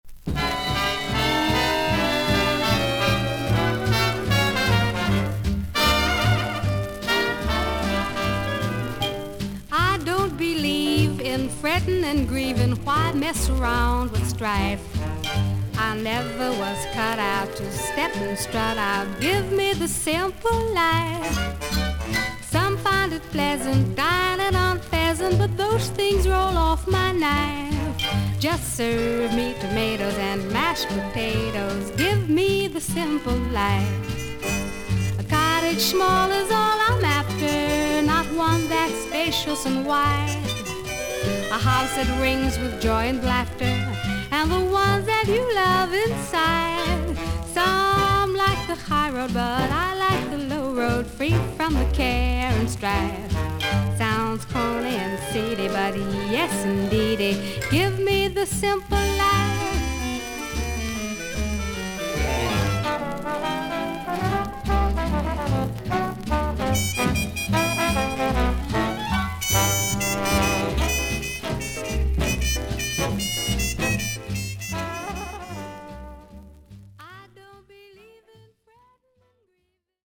少々軽いパチノイズの箇所あり。少々サーフィス・ノイズあり。クリアな音です。
キュート・ヴォイスでしっかりとジャズのセンスを持った希少な女性シンガー。